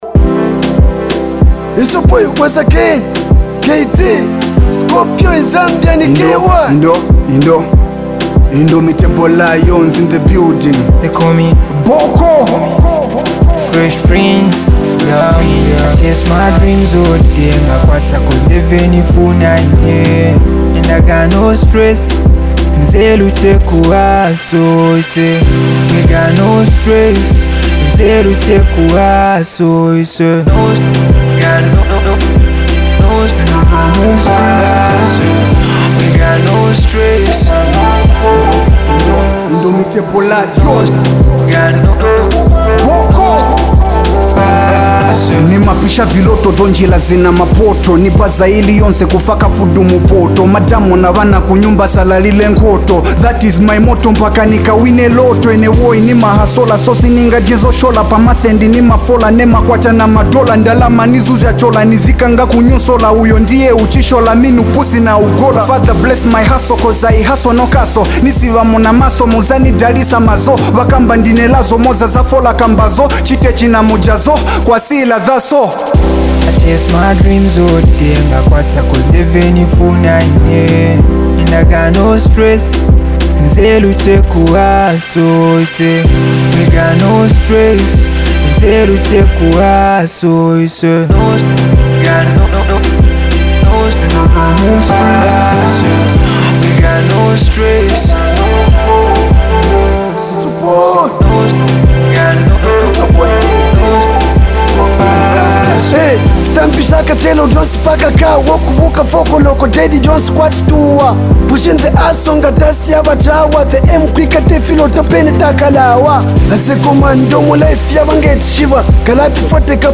With powerful lyrics and energetic delivery
backed by a dynamic beat